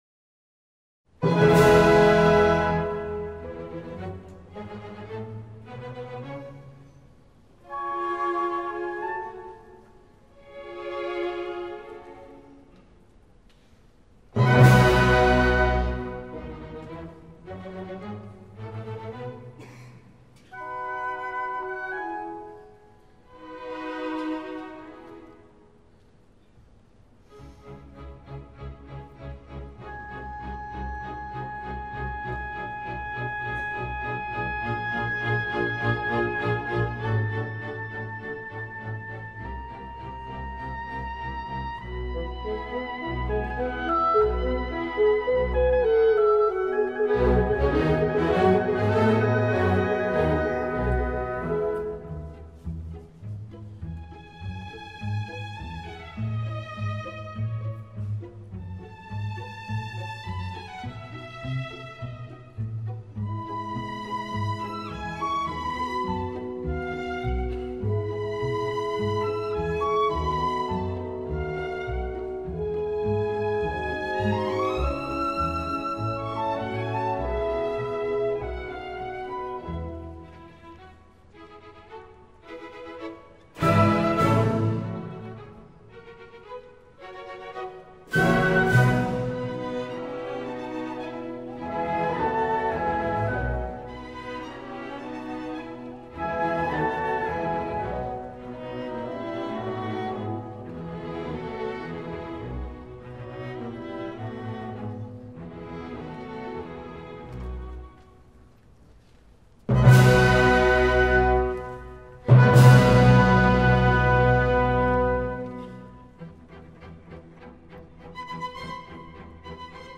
Il CD è stato registrato dall'Orchestra Sinfonica Giovanile del Piemonte dal vivo al concerto per la Festa della Repubblica, presso il teatro Alfieri di Torino (2 giugno 2005).